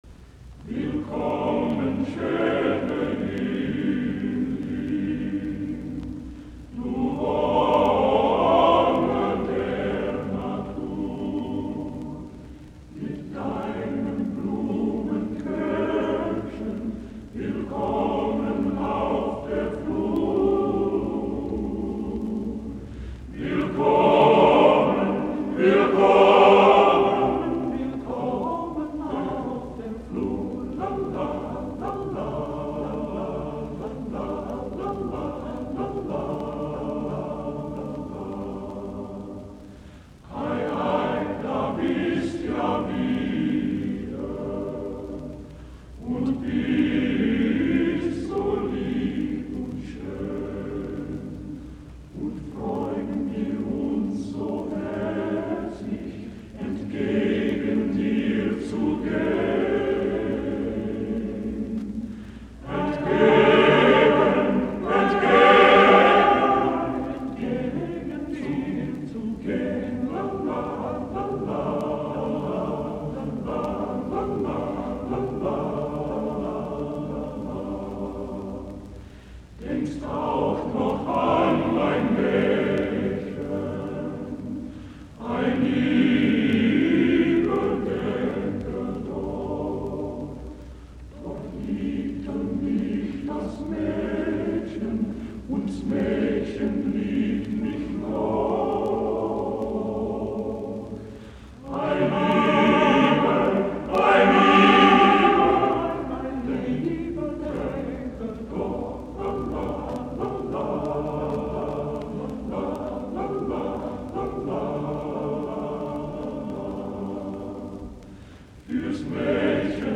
Sweet and low and Schubert songs for male chorus